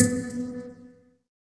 SYN_Pizz2.wav